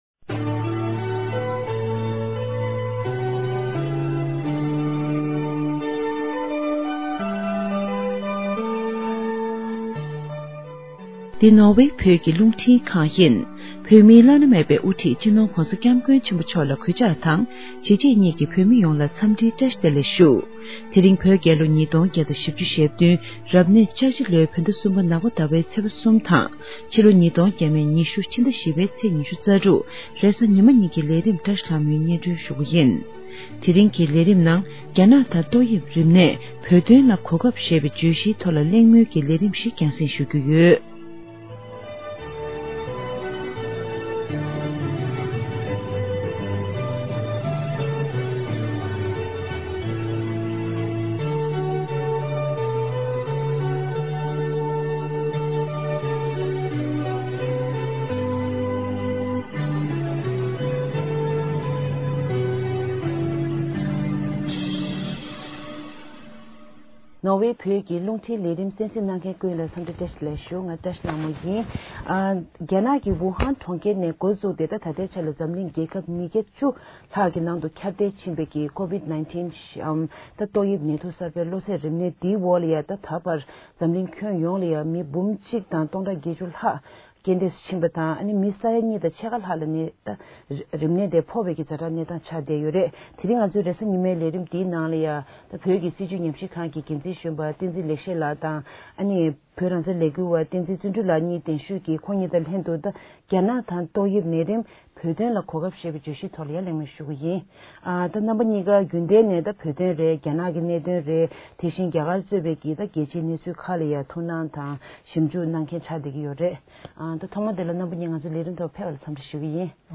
ཉན་པ།: Listen ཕབ་ལེན། Download རྒྱ་ནག་དང་ཏོག་དབྱིབས་ནད་རིམས། བོད་དོན་ལ་གོ་སྐབས་ཐོག་གླེང་མོལ། Sunday 26/04/2020 COVID-19 crisis and China, opportunities for Tibet: Panel Discussion